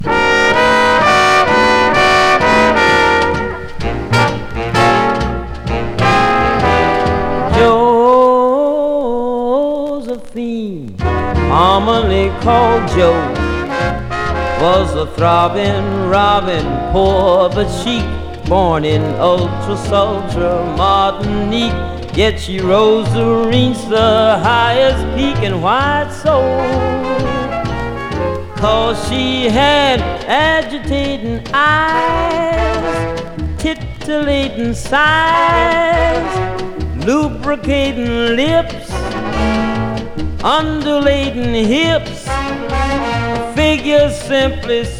演技同様にさまざまな歌い分け、バックバンドの演奏も冴えてます。
Jazz, Pop, Vocal　USA　12inchレコード　33rpm　Mono